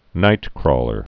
(nītkrôlər)